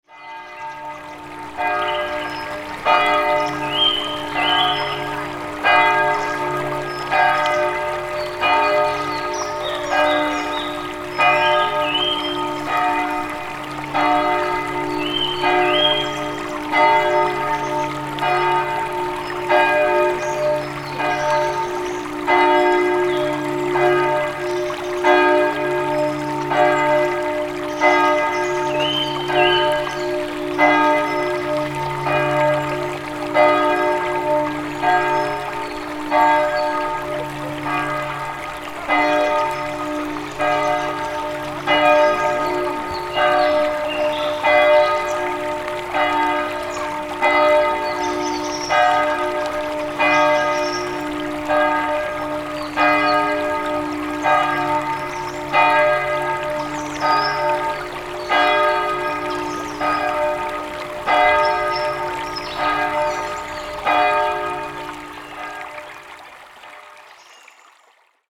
Easter Sound Effect With Bells, Birds, And A Stream
Bell Sound / Sound Effects 22 Feb, 2025 Easter Sound Effect With Bells, Birds, And A Stream Read more & Download...
Easter-sound-effect-with-bells-birds-and-a-stream.mp3